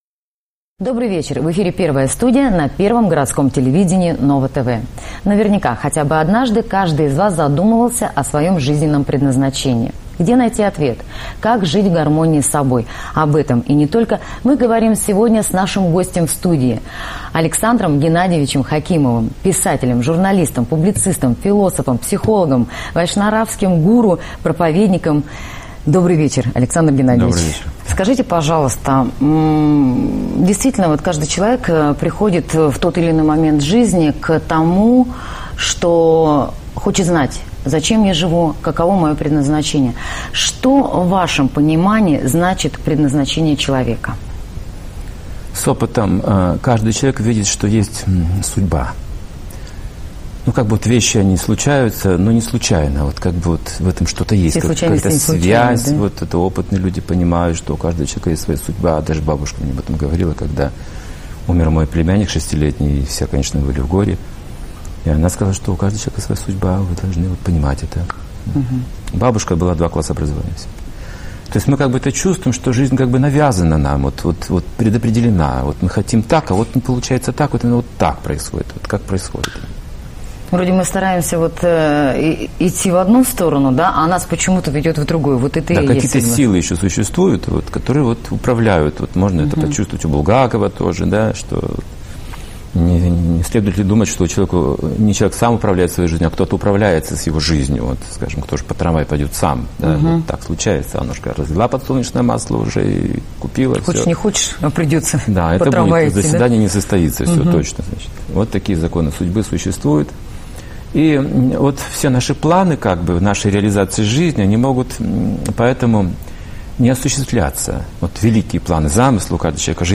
Интервью Ново-ТВ (2015, Новокузнецк)